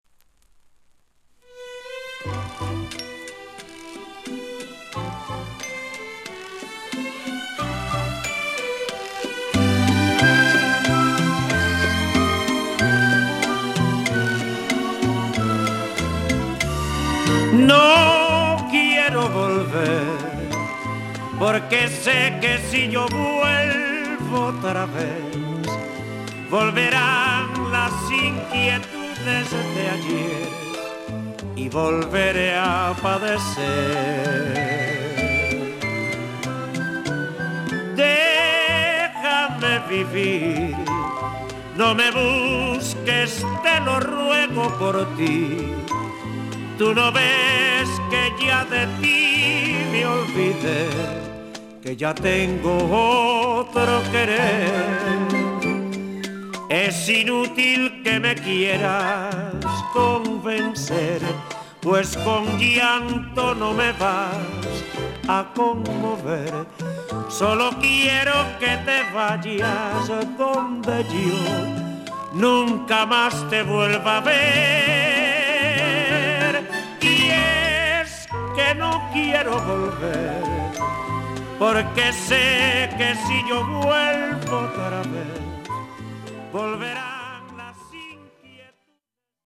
オールド・ハバナの息吹がそのままに録音されている